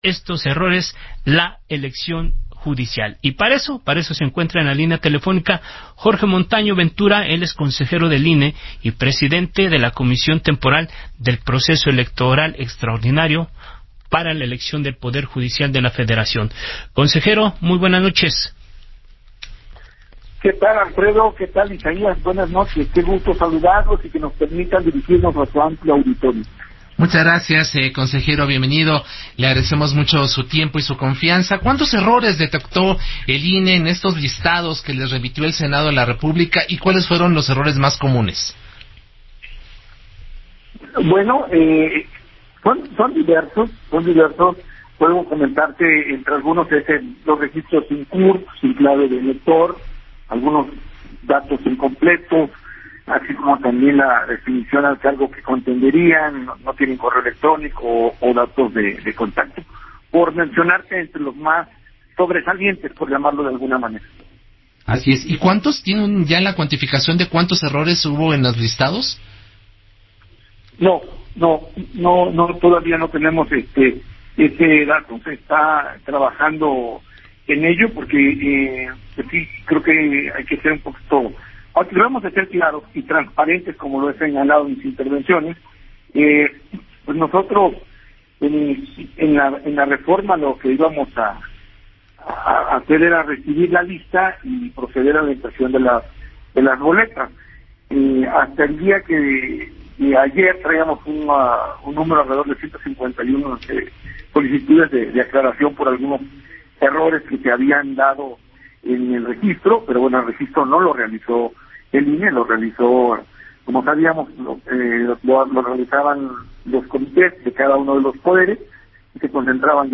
Jorge Montaño en entrevista para El Heraldo Radio